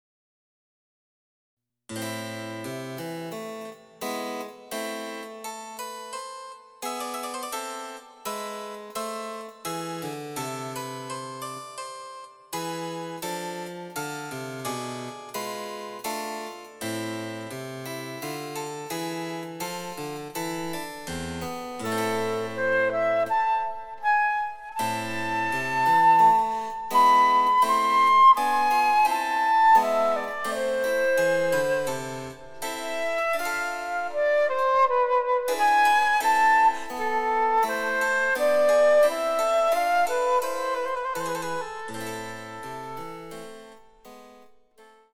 第１楽章はアダージョで、ちょっと長めの前奏があります。
やわらかに悲しみを帯びています。
■フルートによる演奏
チェンバロ（電子楽器）